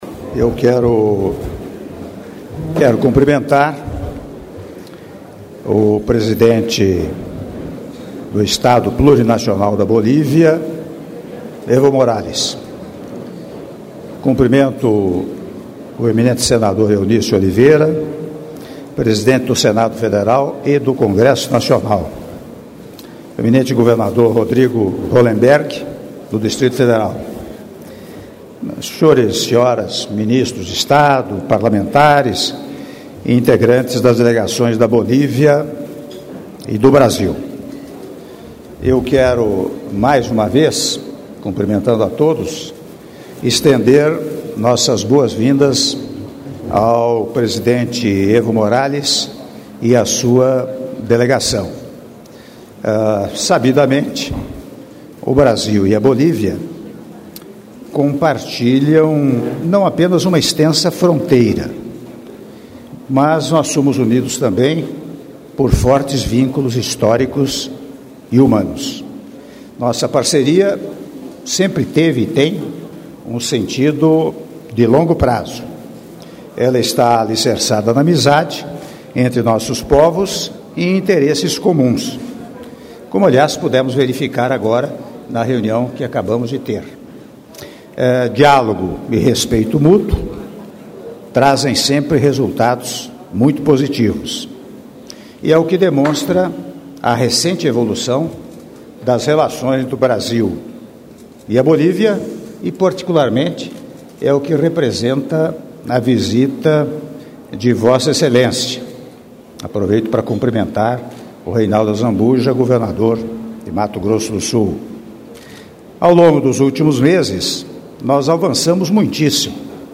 Áudio do brinde do Presidente da República, Michel Temer, durante Almoço em homenagem ao senhor Evo Morales, Presidente do Estado Plurinacional da Bolívia, oferecido pelo senhor Presidente da República - (07min24s) - Brasília/DF